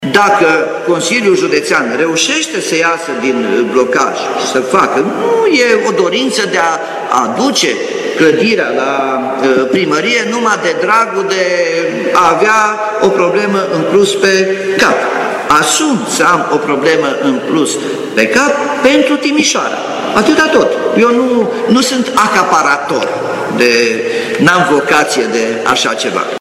Pe acest fond, primarul Nicolae Robu a propus trecerea Muzeului Banatului, de la Consiliul Județean , la primărie: